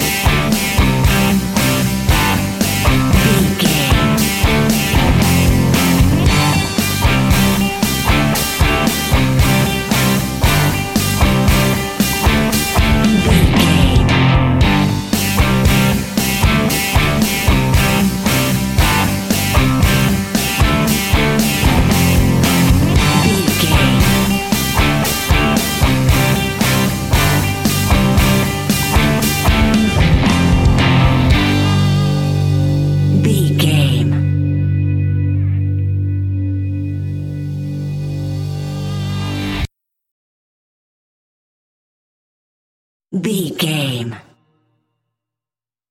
Epic / Action
Ionian/Major
hard rock
heavy rock
distortion
rock guitars
Rock Bass
Rock Drums
heavy drums
distorted guitars
hammond organ